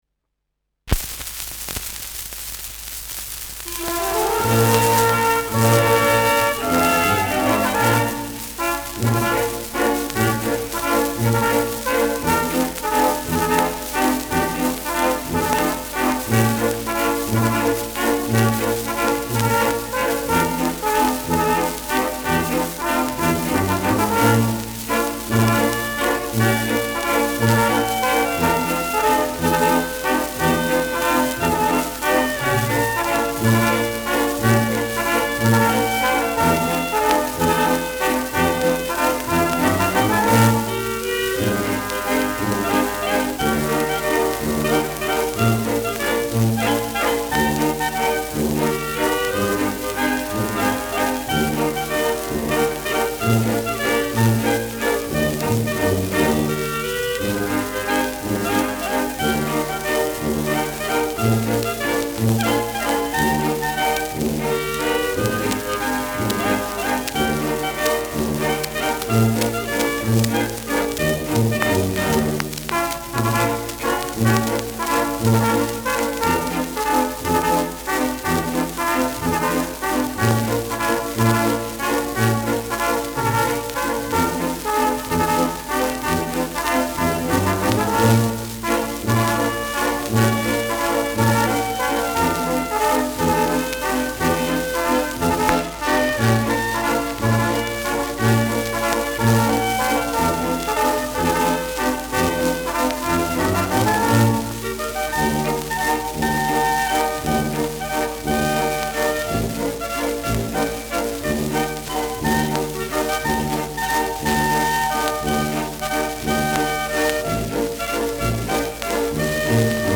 Schellackplatte
präsentes Rauschen : präsentes Knistern : leichtes Knacken bei 1’08’’ : leichtes Nadelgeräusch
Dachauer Bauernkapelle (Interpretation)
[München?] (Aufnahmeort)